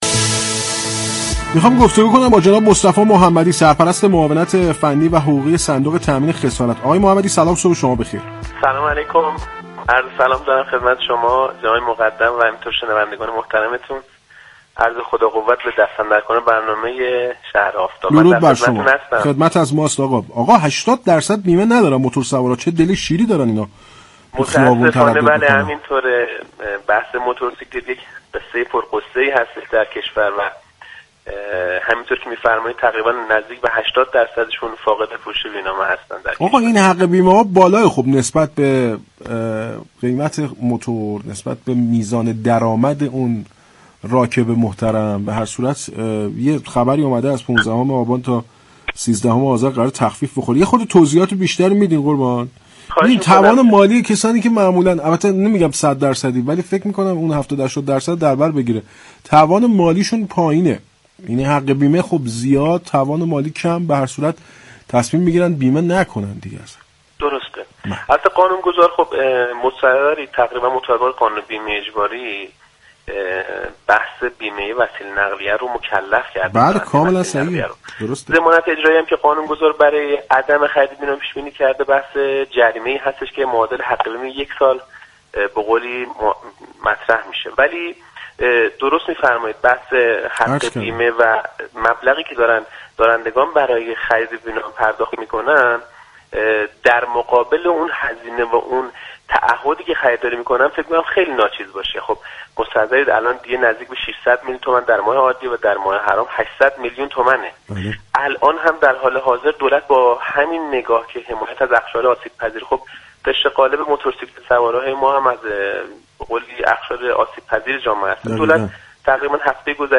در گفت و گو با شهر آفتاب رادیو تهران